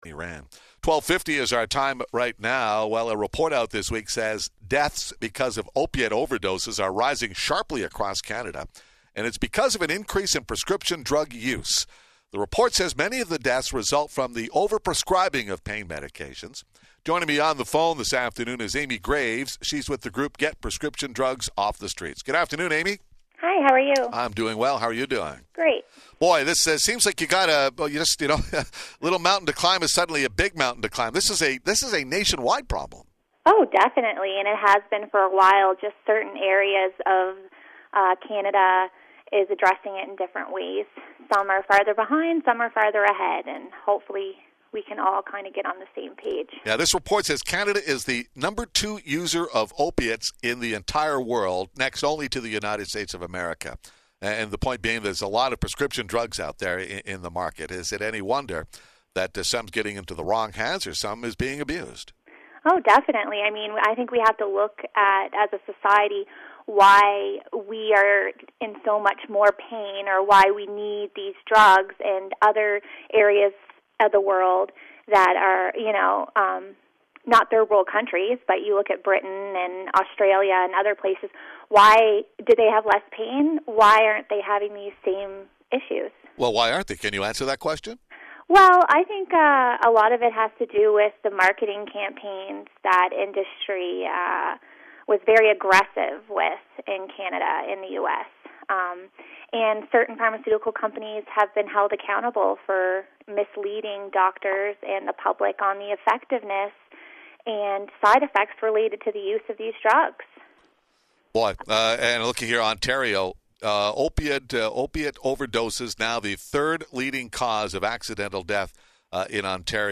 My Interviews · Radio Interviews